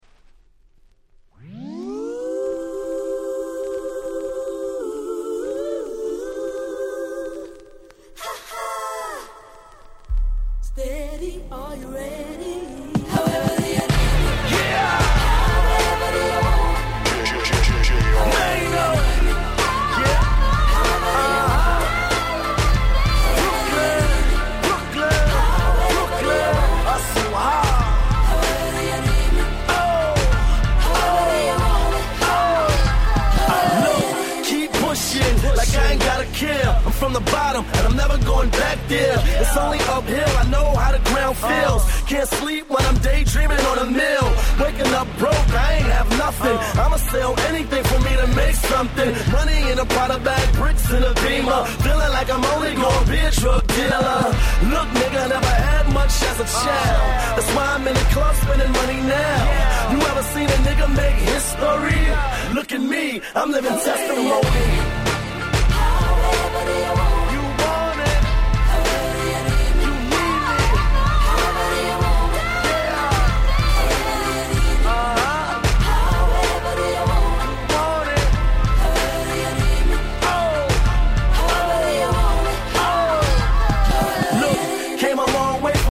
09's Smash Hit Hip Hop !!